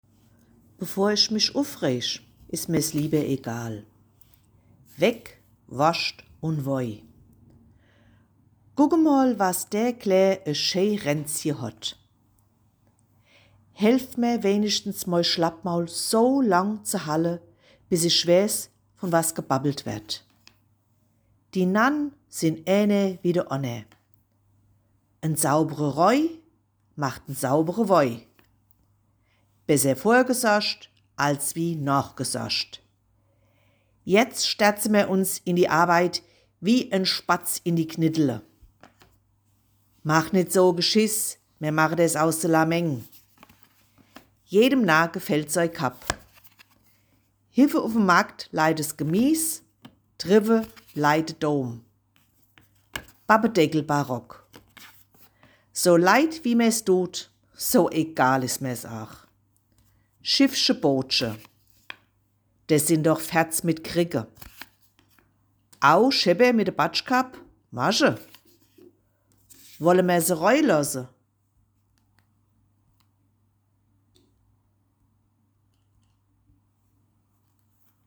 Agenda 2030 - "uff meenzerisch" | Landeshauptstadt Mainz